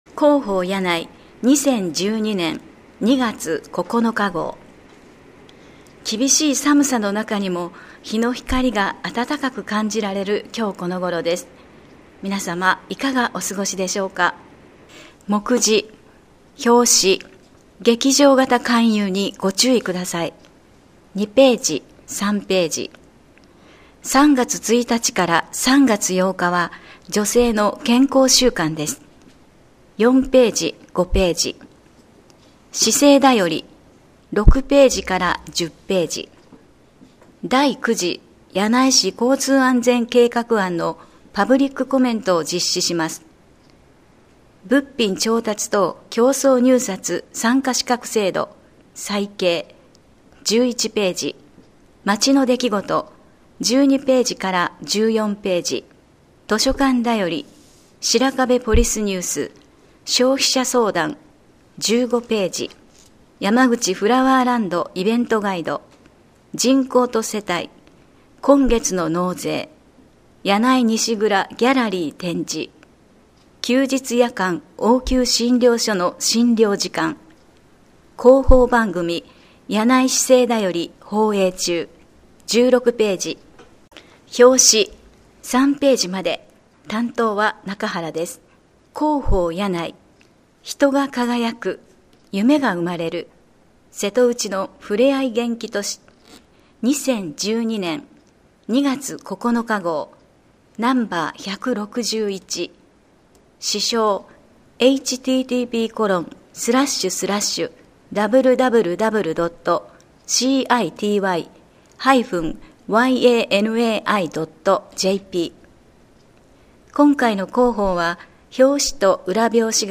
声の広報（音訳版：発行後1週間程度で利用可能）